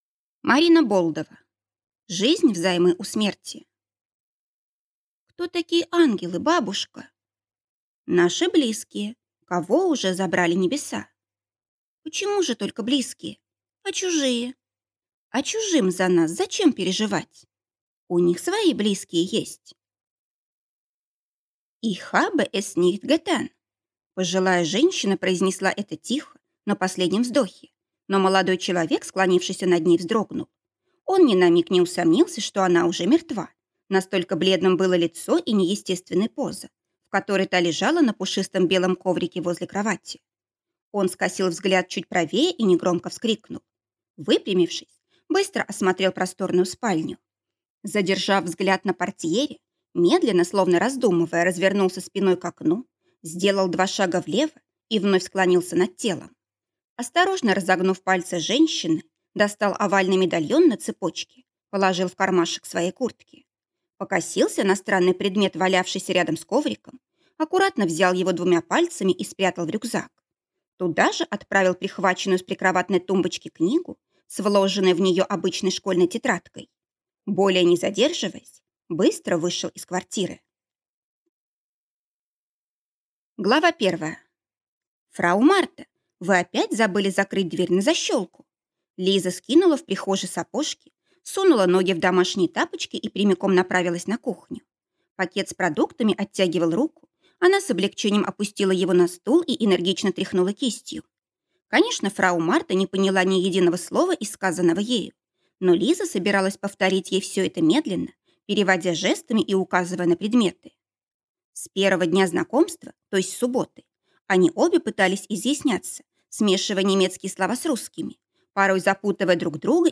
Аудиокнига Жизнь взаймы у смерти | Библиотека аудиокниг